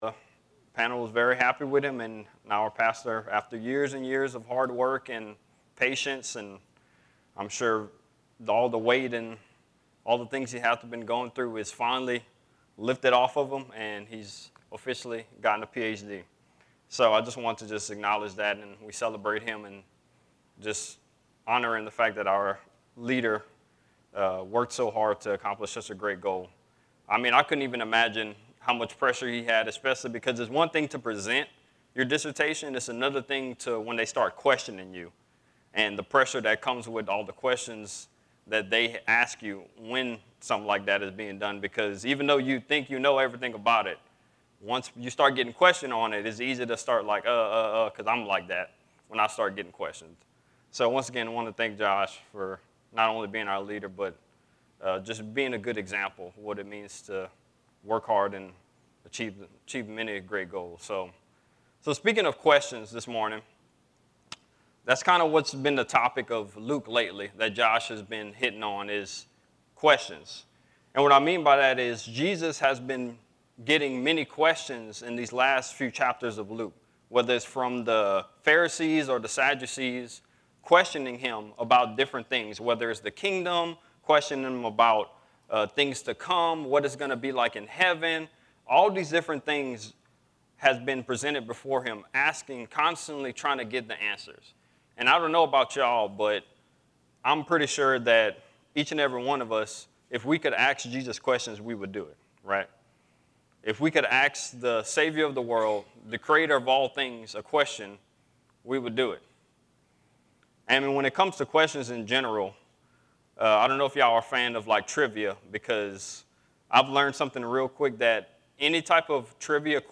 A message from the series "Luke." David calls Jesus Lord